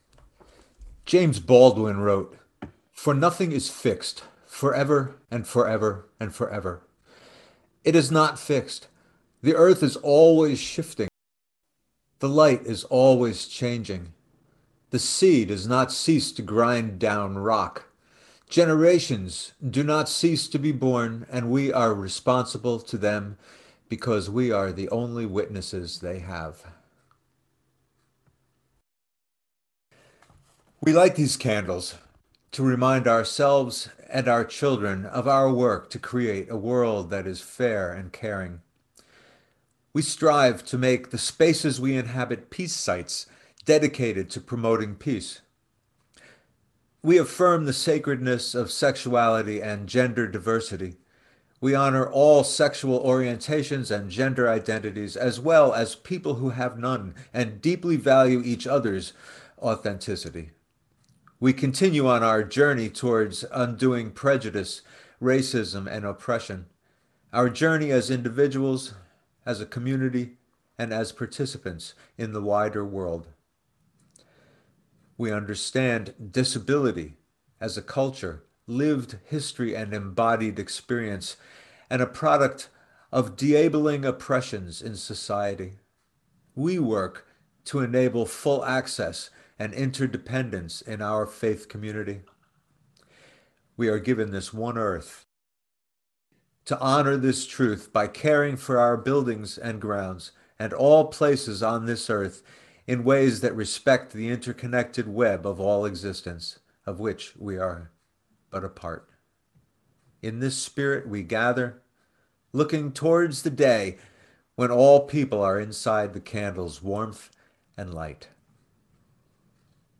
Sunday-Service-May-9-2021-Final